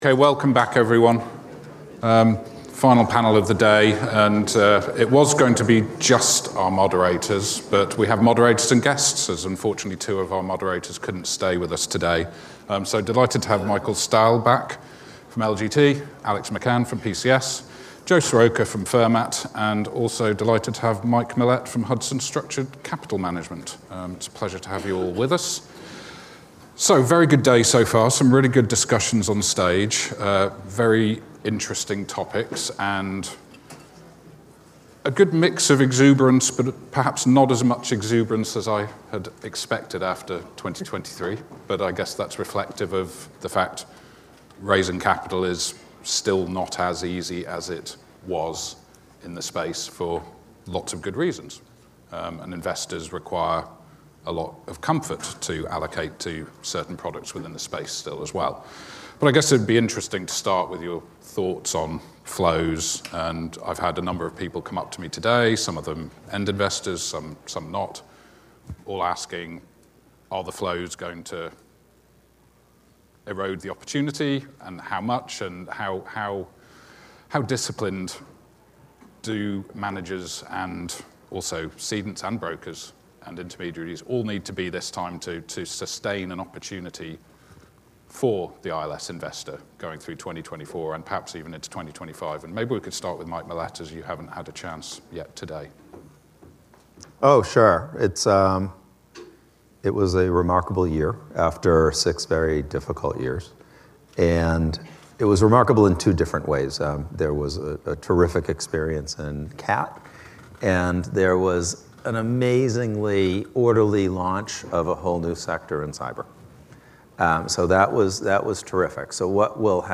This podcast episode features a panel discussion with insurance-linked securities (ILS) market experts and was the fifth and final session of the day at our Artemis ILS NYC 2024 conference, held in New York on February 9th 2024.